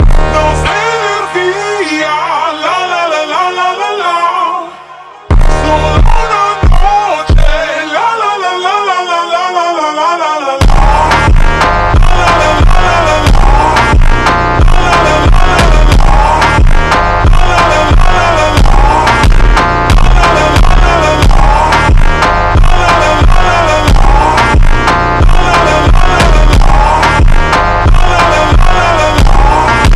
Dance
Жанр: Танцевальные